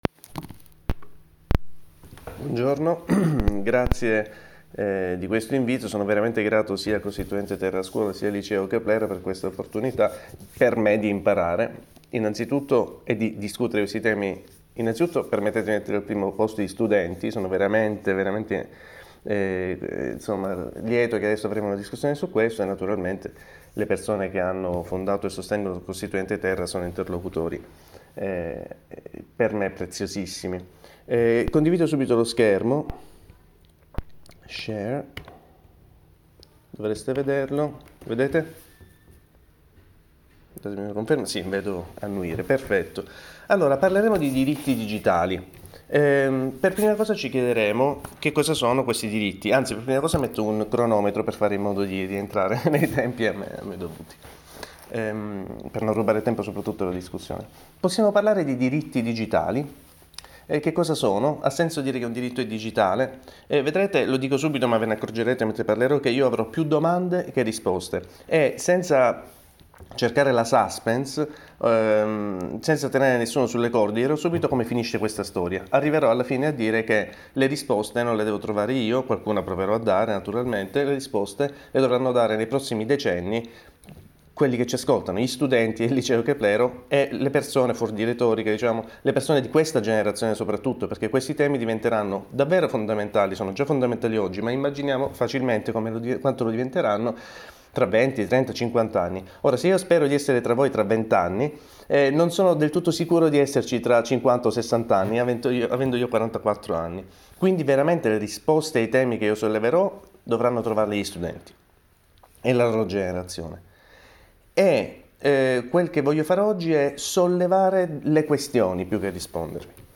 Talk on digital rights to the students of Liceo Keplero high school, Rome, Italy, organized by Costituente Terra